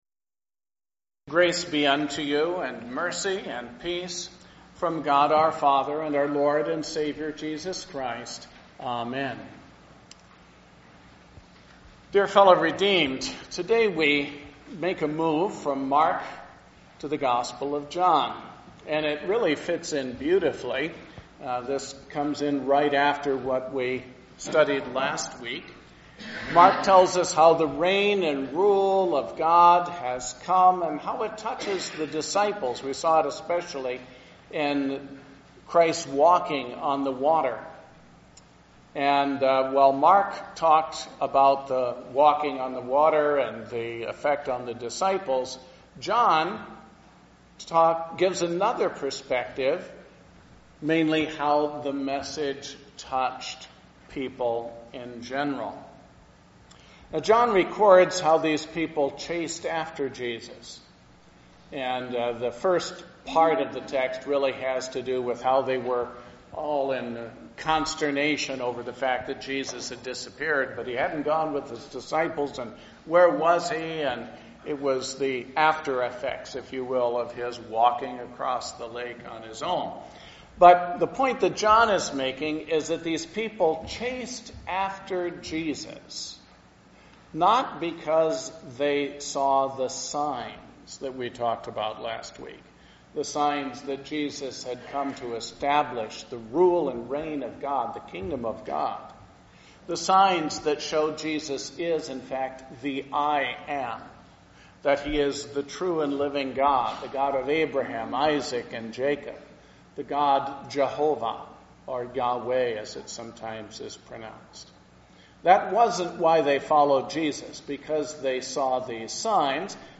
Sermon based on John 6:35–51.